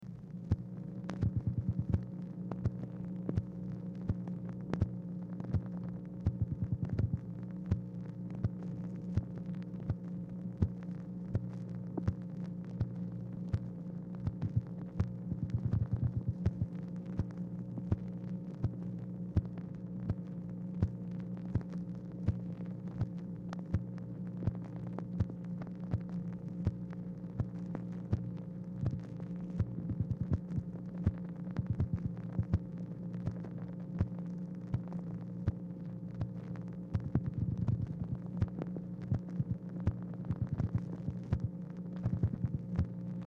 Telephone conversation # 4278, sound recording, MACHINE NOISE, 7/20/1964, time unknown | Discover LBJ
Format Dictation belt